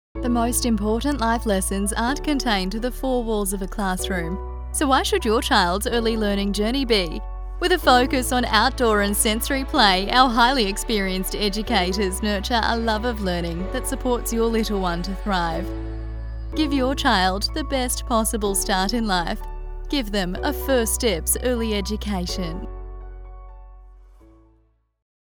Female
English (Australian)
Yng Adult (18-29), Adult (30-50)
Radio Commercials
Childcare Commercial
0403Childcare_Commercial.mp3